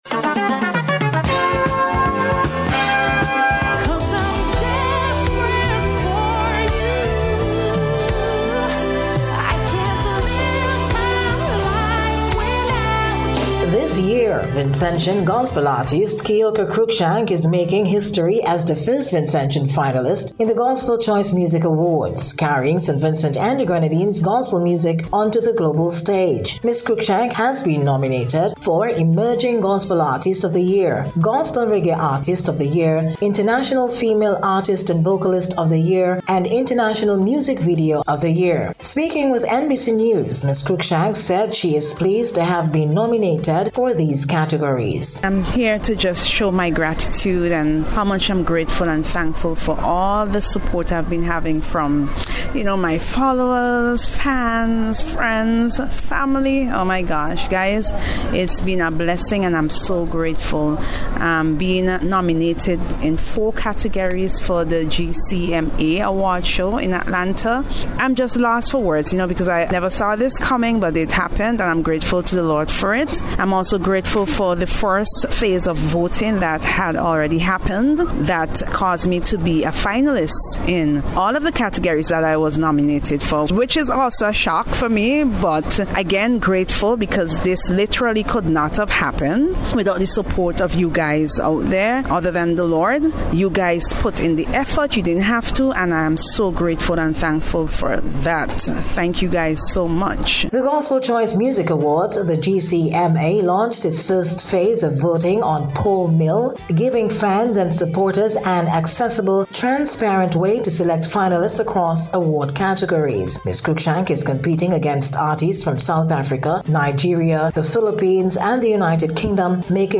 GCM-AWARDS-SPECIAL-REPORT.mp3